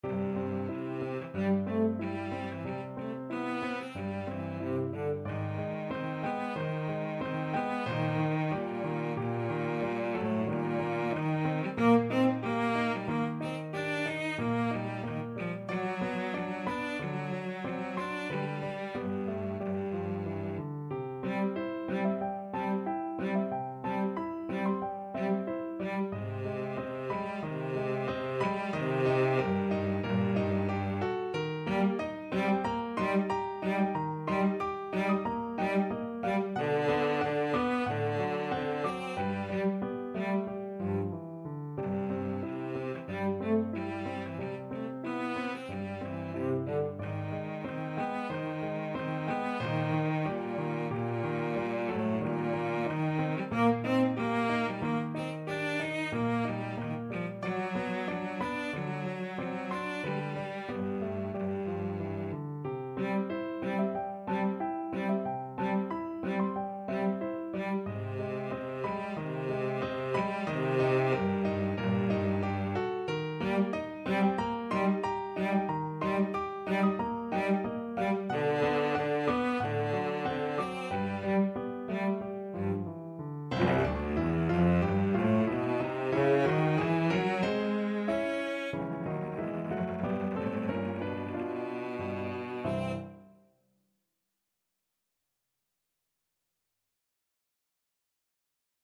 4/4 (View more 4/4 Music)
Andantino =92 (View more music marked Andantino)
C3-Eb5
Classical (View more Classical Cello Music)